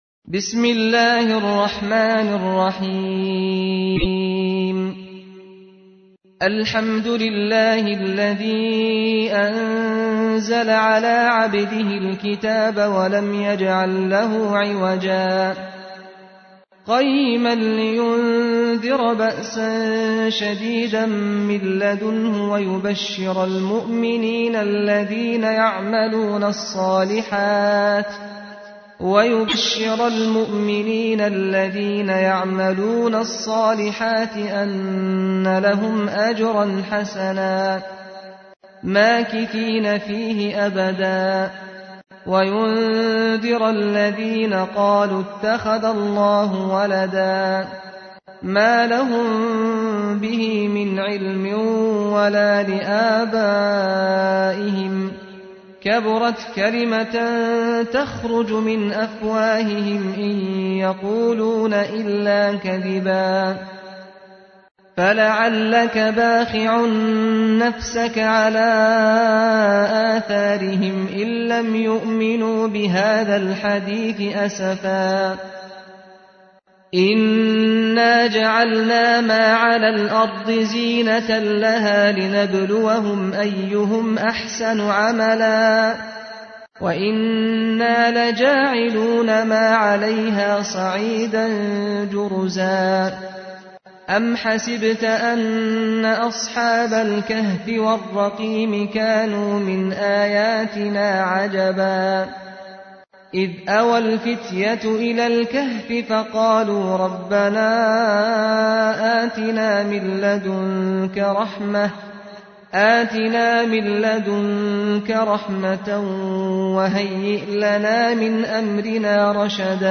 تحميل : 18. سورة الكهف / القارئ سعد الغامدي / القرآن الكريم / موقع يا حسين